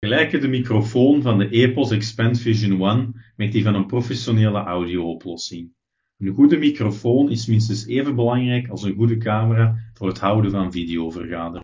We also test whether the quality of the microphone can keep up with that of a professional audio setup (Philips Smartmeeting PSE0540).
Fragment 3 (Philips Smartmeeting PSE0540):